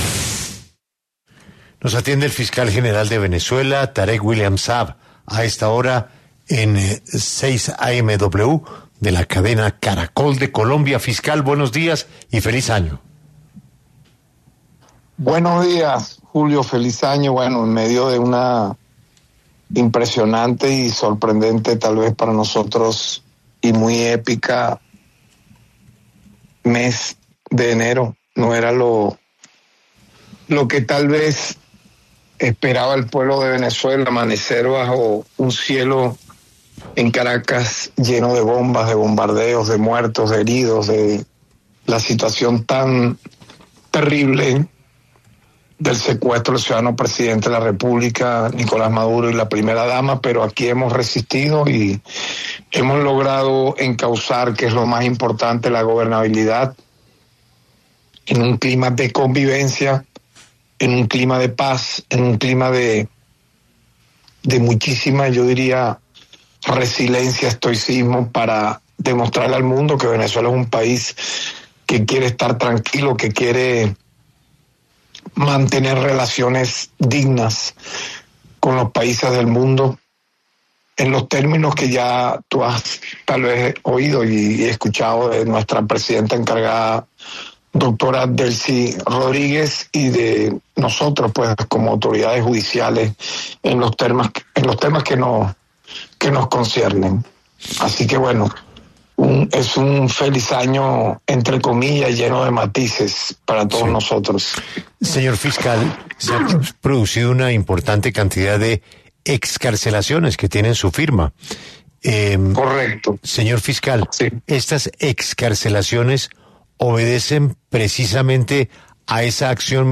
El Fiscal General de Venezuela, Tarek William Saab, se refirió en 6AMW a las excarcelaciones de presos políticos asegurando que las cifras de las ONGs son erradas